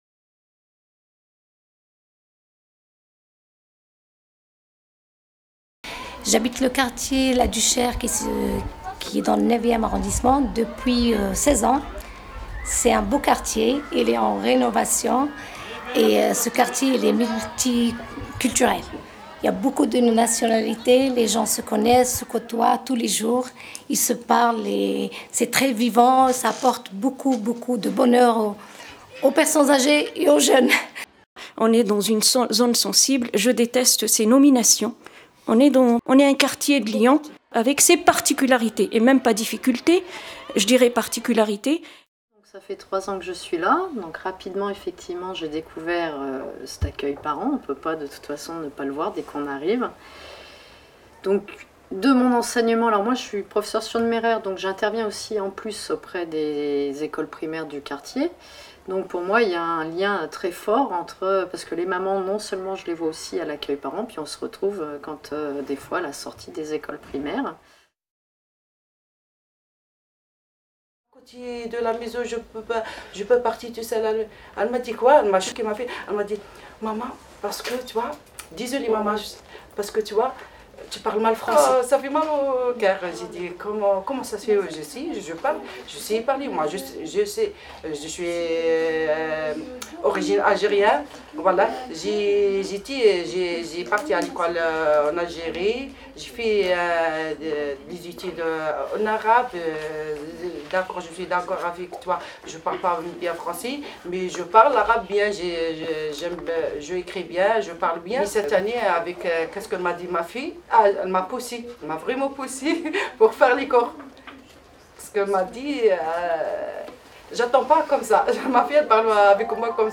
Se perfectionner en français, paroles de parents d'élèves   0:01:08
Donner des cours d'anglais, parole d'enseignante  0:04:10
Faire sa place dans un établissement, parole d'enseignant  0:04:41